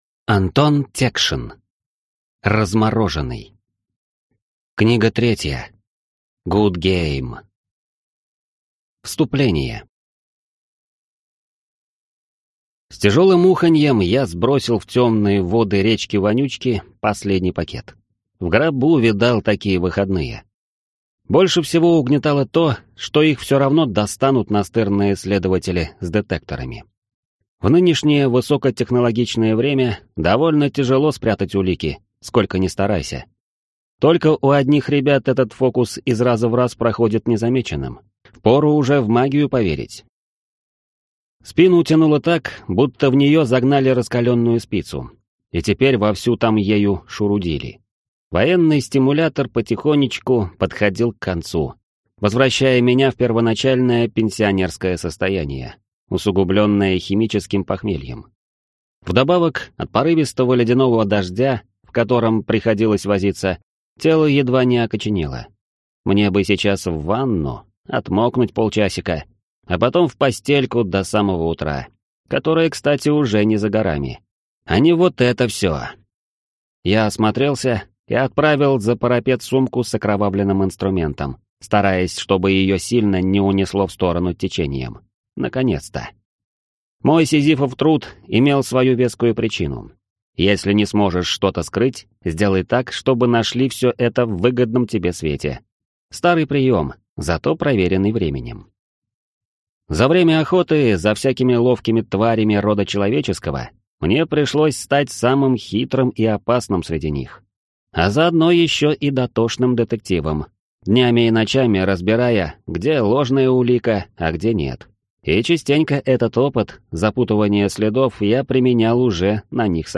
Аудиокнига Размороженный. Книга 3. GoodGame | Библиотека аудиокниг